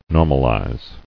[nor·mal·ize]